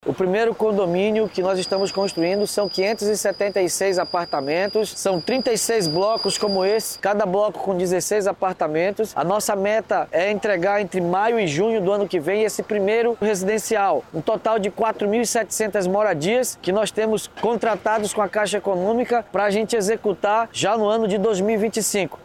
Durante uma vistoria à obra, na manhã desta quarta-feira 18/12, o prefeito de Manaus, Davi Almeida, disse que a previsão de entrega é para o segundo semestre de 2025.
SONORA-1-OBRA-RESIDENCIAL-.mp3